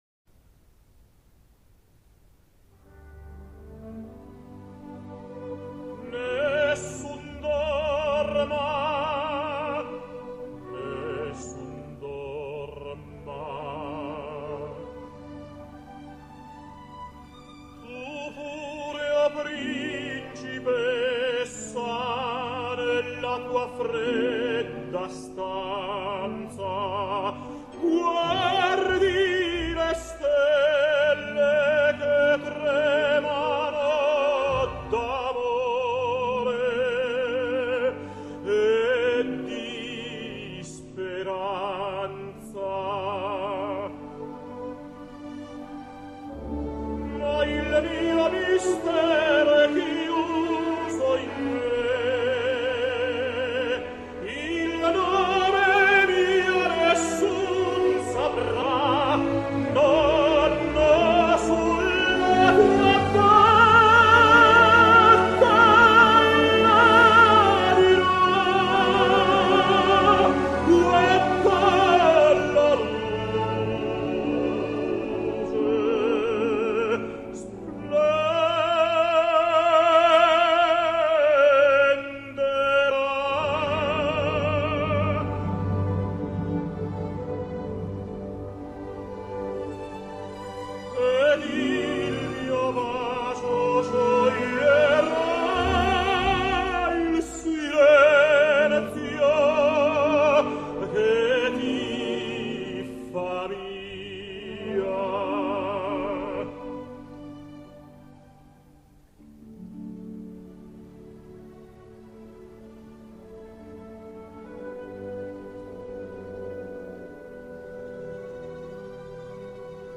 Tenors singing Nessun dorma
Carreras